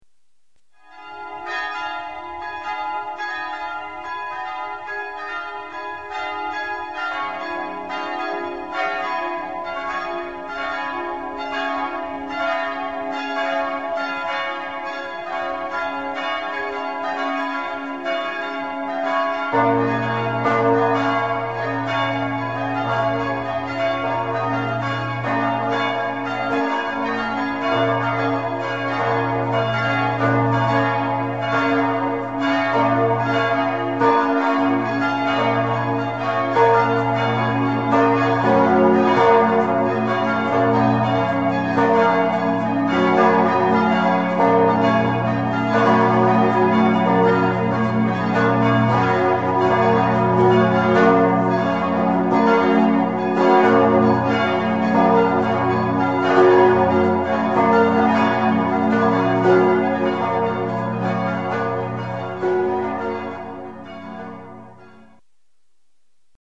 Glockenklang aus 8 europäischen Kirchen
Terz – Glocken (Kirche St. Stephan Karlsruhe, Deutschland)
4_terz_glocken.mp3